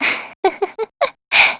Isaiah-Vox_Happy2_kr.wav